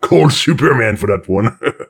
woodboxdestroyed02.ogg